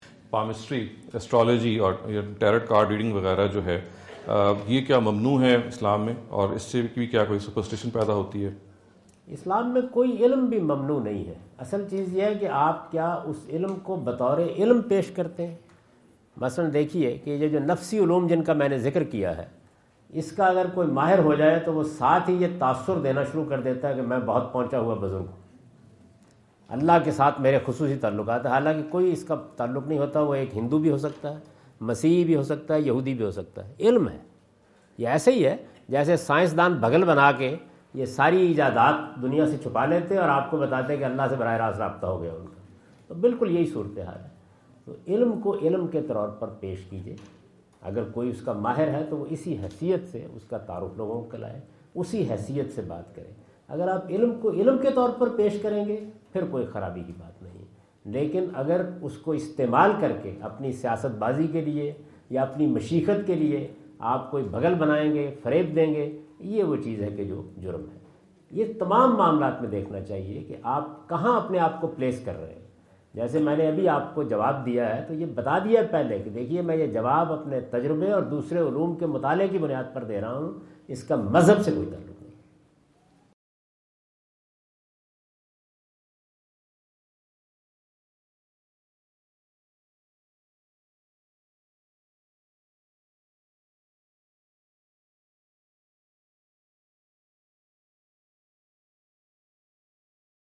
Javed Ahmad Ghamidi answer the question about "Do Palmistry and Astrology Promote Superstitions?" asked at Corona (Los Angeles) on October 22,2017.
جاوید احمد غامدی اپنے دورہ امریکہ 2017 کے دوران کورونا (لاس اینجلس) میں "کیا علم النجوم توہم پرستی کو فروغ دیتا ہے؟" سے متعلق ایک سوال کا جواب دے رہے ہیں۔